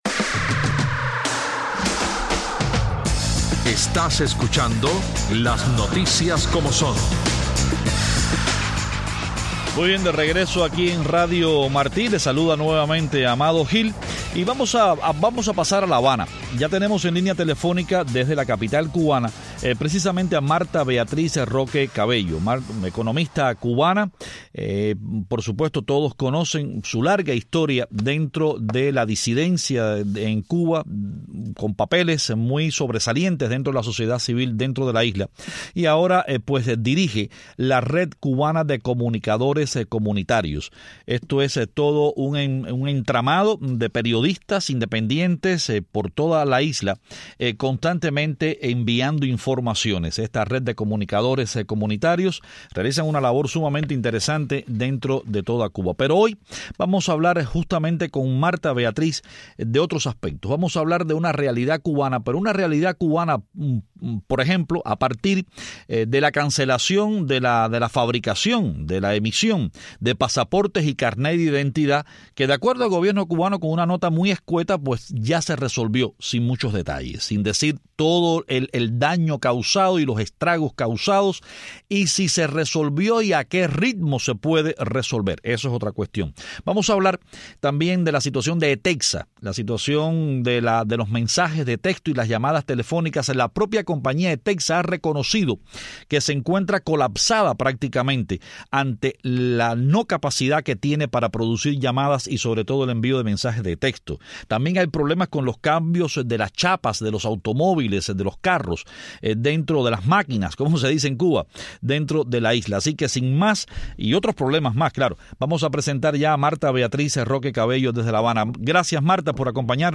Cuba anunció que restableció la emisión de pasaportes y de carnés de identidad suspendidas hace casi una semana. Desde La Habana, nuestra invitada es la economista independiente y dirigente de la Red Cubana de Comunicadores Comunitarias, Martha Beatriz Roque Cabello.